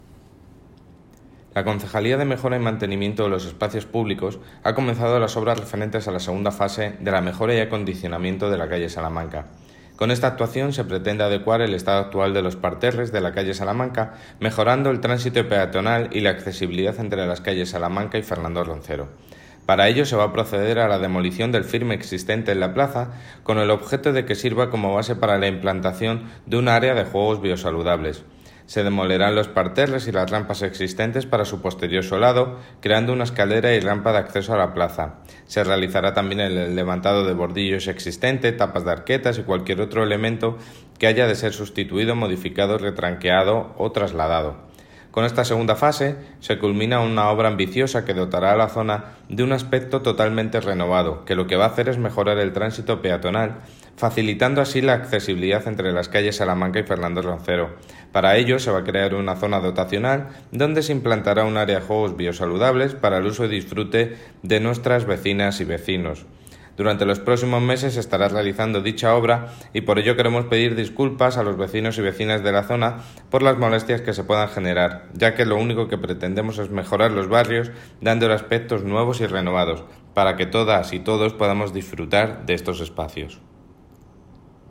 David Muñoz, concejal de Mejora y Mantenimiento de los Espacios Públicos, sobre Calle Salamanca